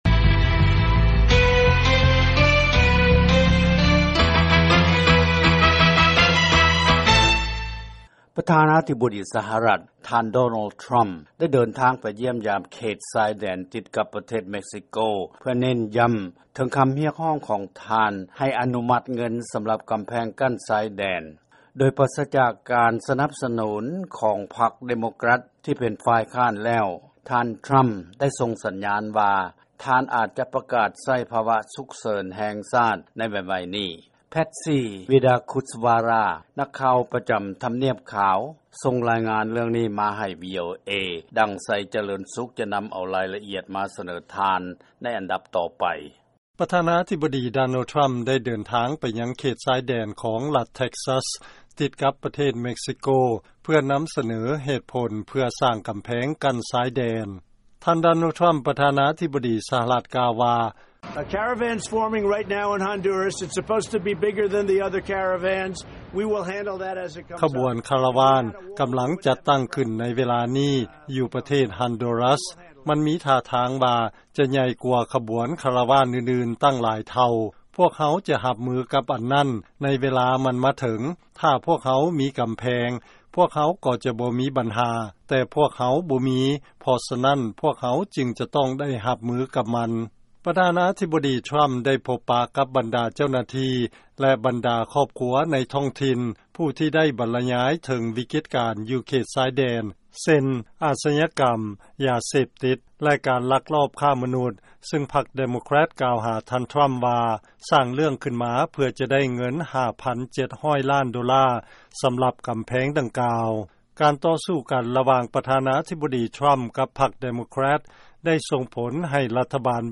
ເຊີນຟັງລາຍງານ ປ. ດໍໂນລ ທຣຳ ເດີນທາງ ໄປຢ້ຽມຢາມ ເຂດຊາຍແດນ ເພື່ອນຳສະເໜີ ເຫດຜົນ ເພື່ອສ້າງກຳແພງ